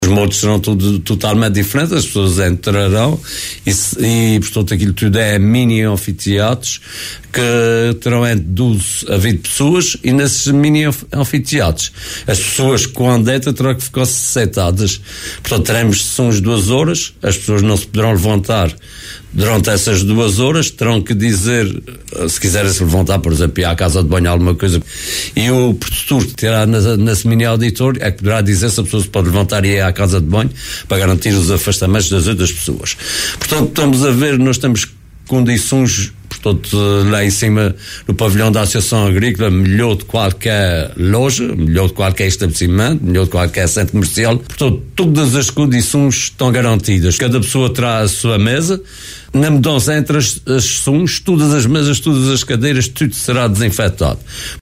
em entrevista à Atlântida, explicou que nestes auditórios serão apresentados e oferecidos para prova os vinhos do produtor.